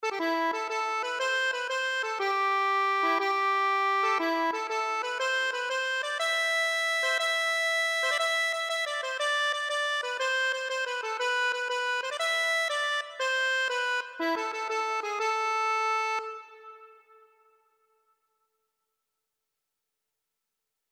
6/8 (View more 6/8 Music)
E5-E6
A minor (Sounding Pitch) (View more A minor Music for Accordion )
Accordion  (View more Easy Accordion Music)
Traditional (View more Traditional Accordion Music)